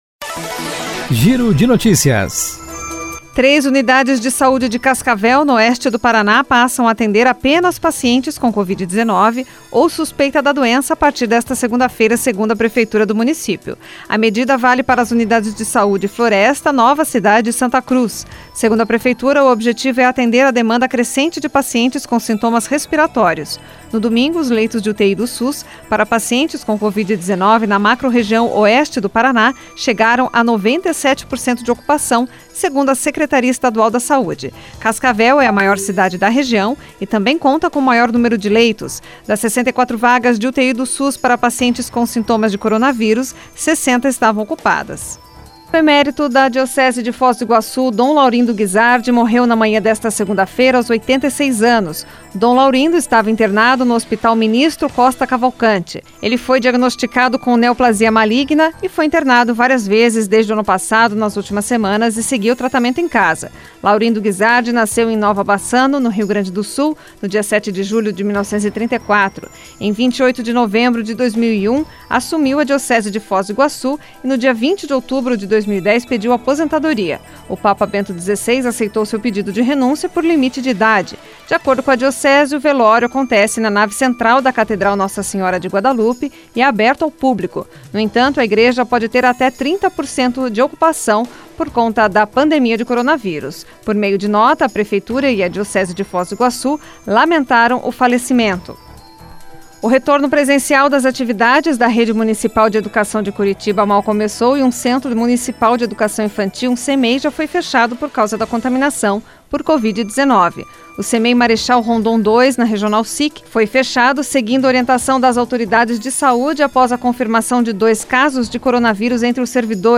Giro de Notícias Tarde COM TRILHA